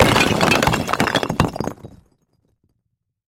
На этой странице собраны разнообразные звуки, связанные с кирпичами: от стука при строительстве до грохота падения.
Звук рушащейся кирпичной стены